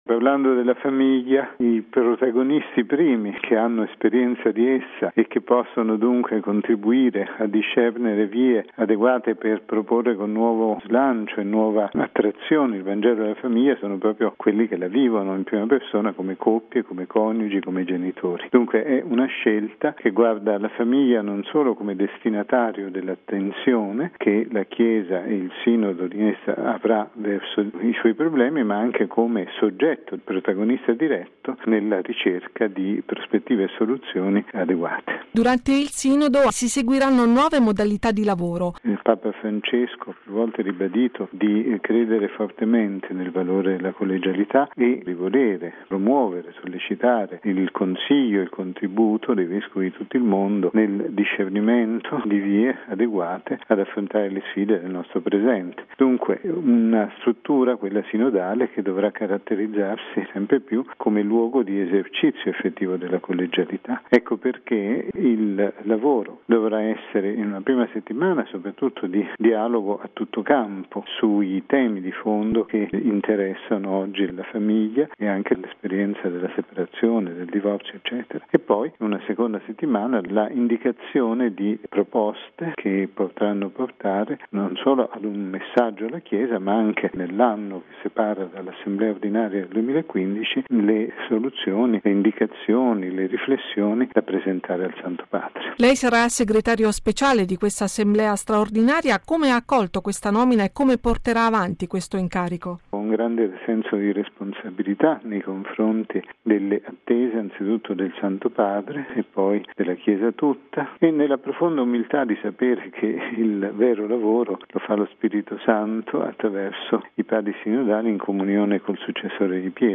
ha intervistato mons. Bruno Forte, arcivescovo di Chieti-Vasto e segretario speciale dell’Assemblea